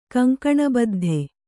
♪ kaŋkaṇa baddhe